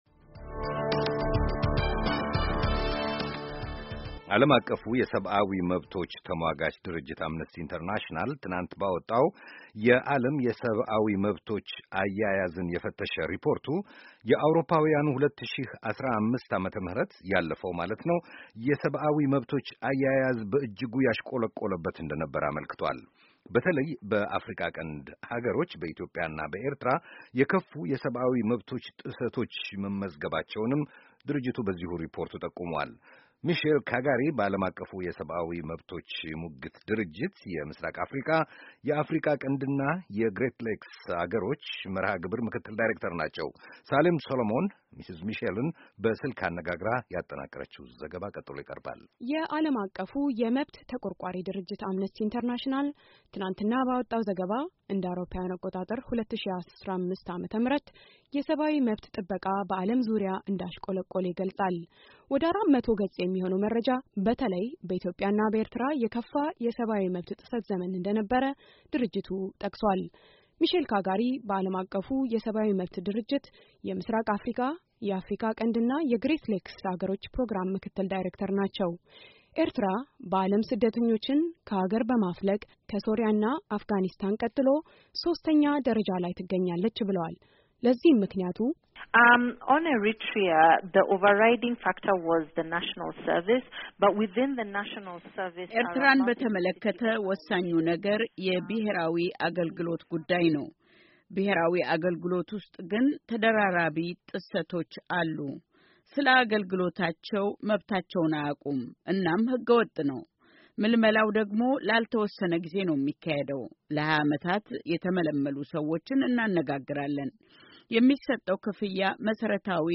በቴሌፎን